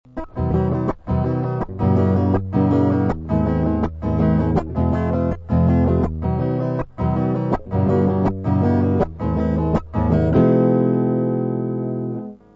Проигрыш: